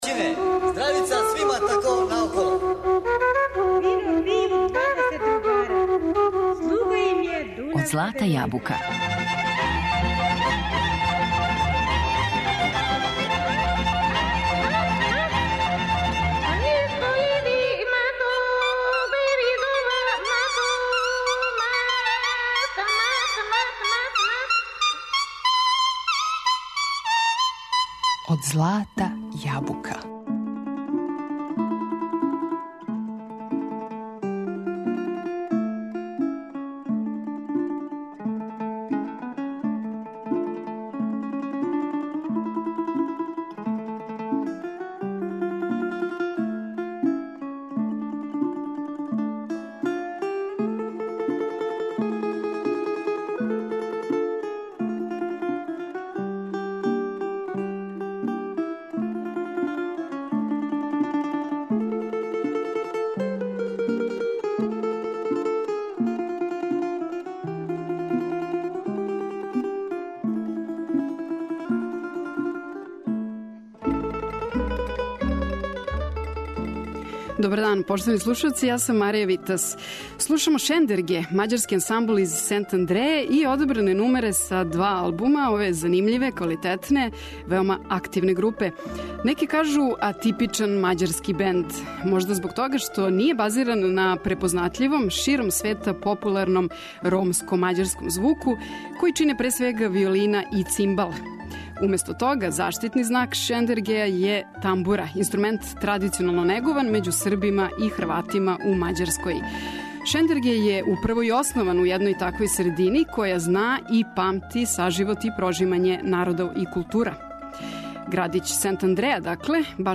тамбура